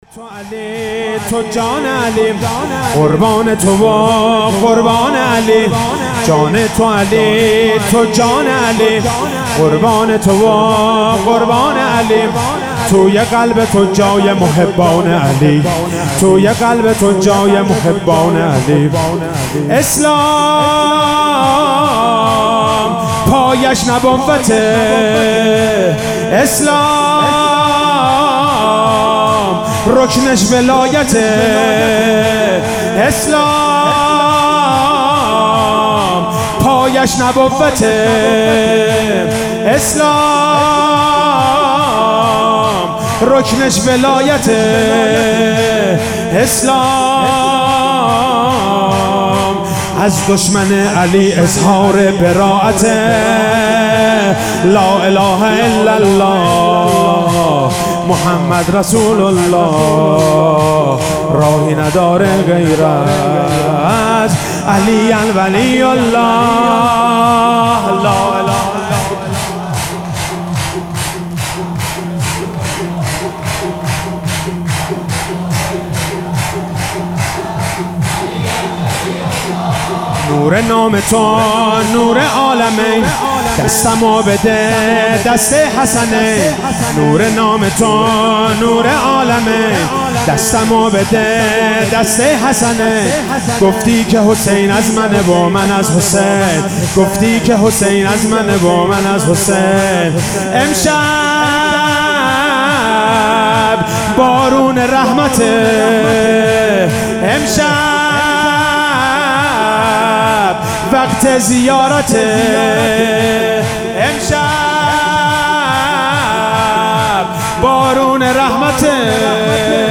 شب ولادت پیامبر(ص) و امام صادق(ع) 98 - سرود - جان تو علی تو جان علی